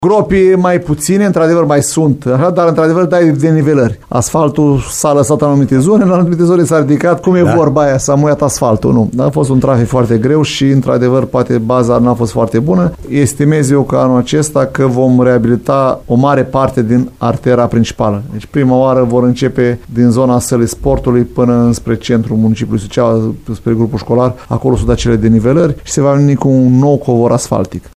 Viceprimarul LUCIAN HARȘOVSCHI a declarat pentru Radio VIVA FM că, aproape zilnic, primește reclamații din partea șoferilor, care “își distrug mașinile pe valurile din carosabil”.